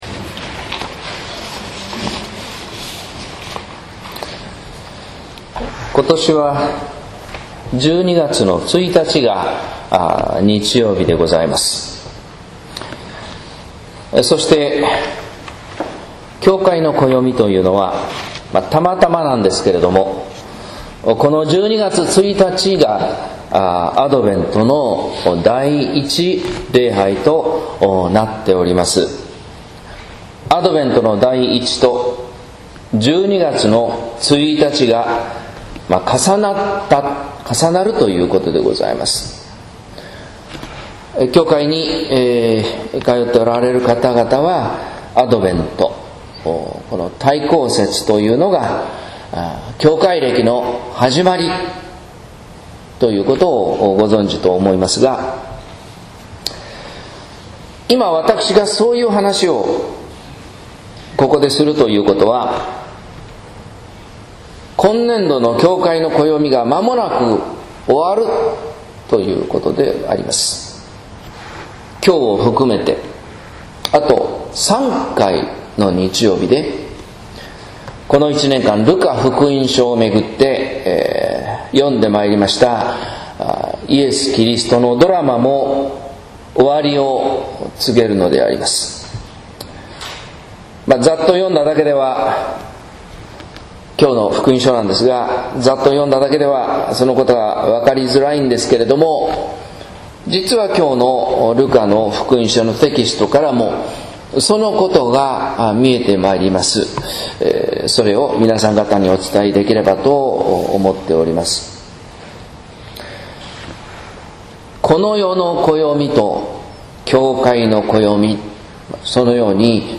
説教「神の暦とこの世の暦」（音声版） | 日本福音ルーテル市ヶ谷教会